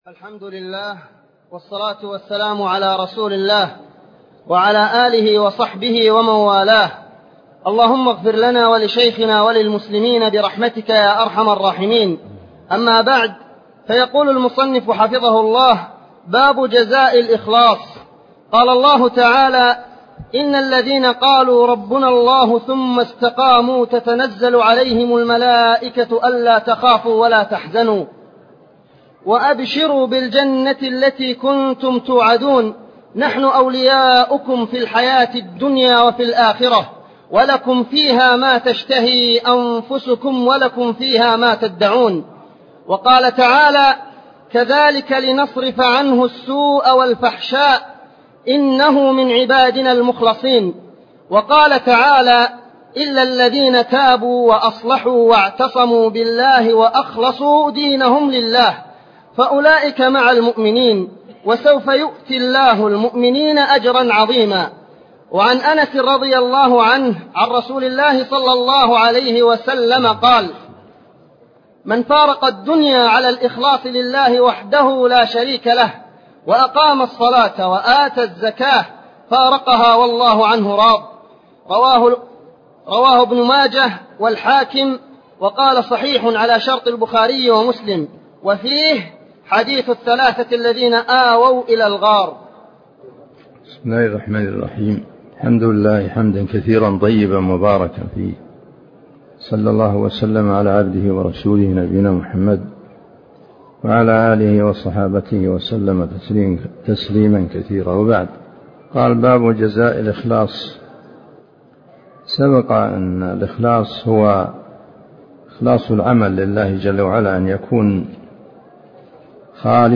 تفاصيل المادة عنوان المادة الدرس (39) شرح المنهج الصحيح تاريخ التحميل الأحد 15 يناير 2023 مـ حجم المادة 20.18 ميجا بايت عدد الزيارات 206 زيارة عدد مرات الحفظ 102 مرة إستماع المادة حفظ المادة اضف تعليقك أرسل لصديق